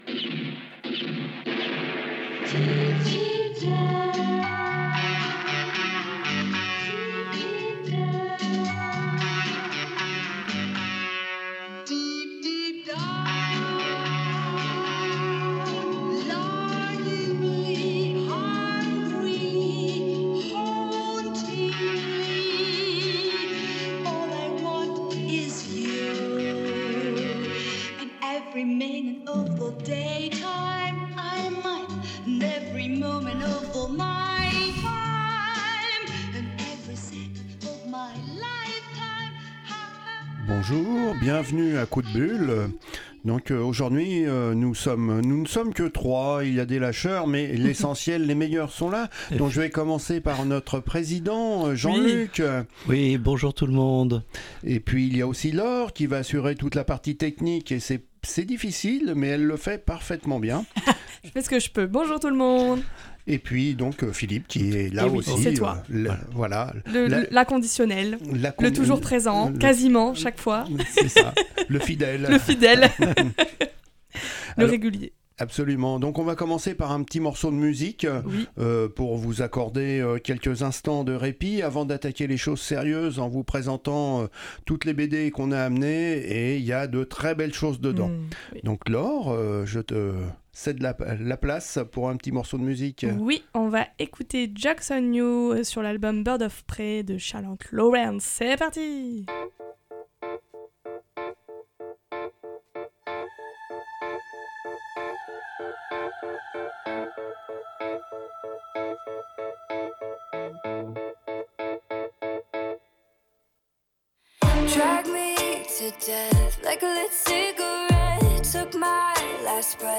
Une émission spéciale « coup de cœur » où chaque intervenant nous propose une lecture qui a retenu son attention. Des histoires hors du commun, qui bouleversent des vies et font naitre l’espoir, tel est le leitmotiv de cette fournée de coup de bulles.